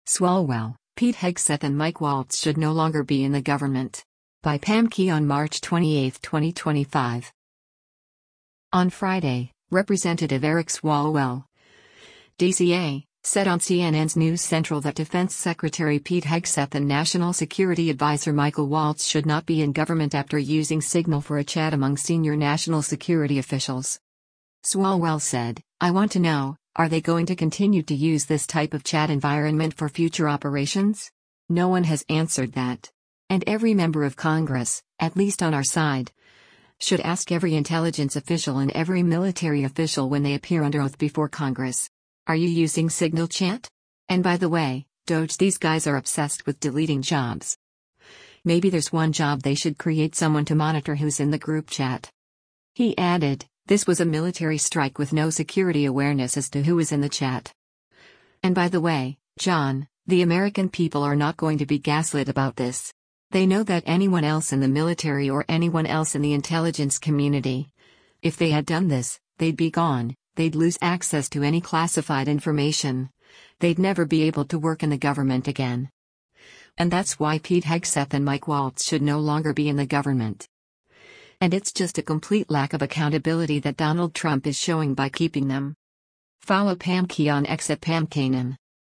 On Friday, Rep. Eric Swalwell (D-CA) said on CNN’s “News Central” that Defense Secretary Pete Hegseth and National Security Adviser Michael Waltz should not be in government after using Signal for a chat among senior national security officials.